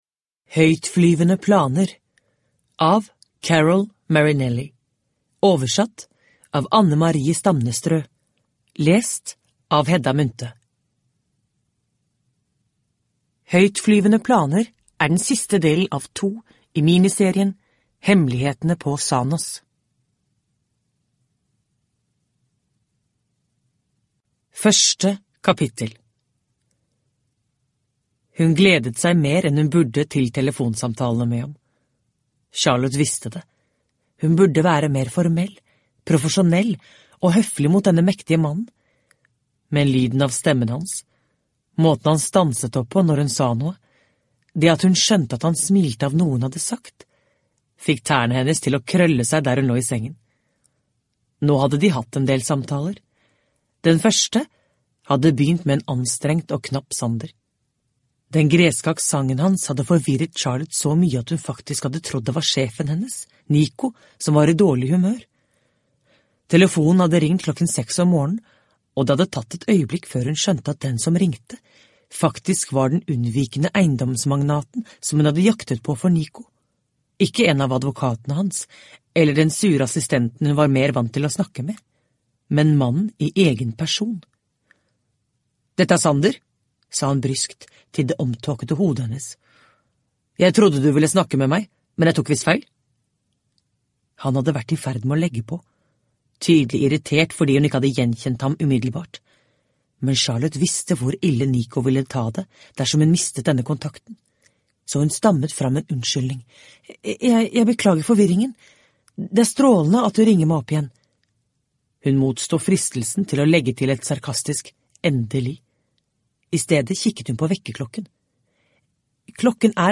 Høytflyvende planer – Ljudbok – Laddas ner